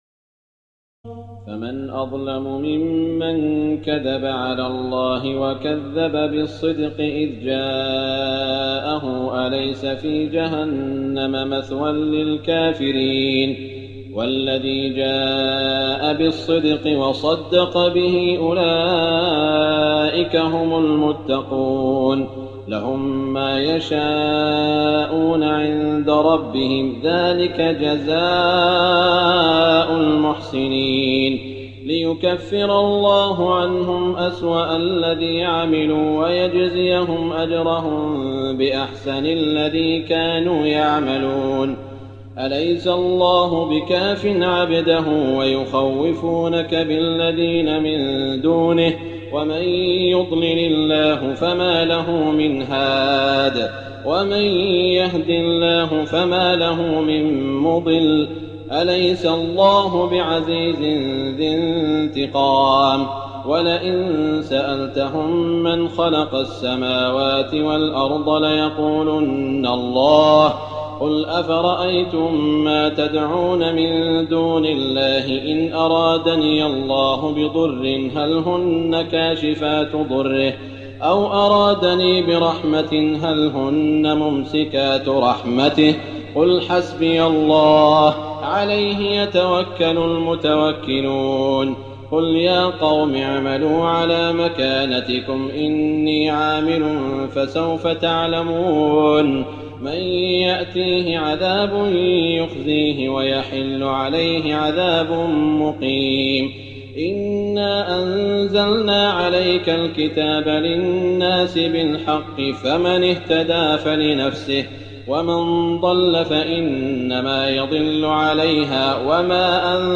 تراويح ليلة 23 رمضان 1419هـ من سور الزمر (32-75) و غافر (1-40) Taraweeh 23 st night Ramadan 1419H from Surah Az-Zumar and Ghaafir > تراويح الحرم المكي عام 1419 🕋 > التراويح - تلاوات الحرمين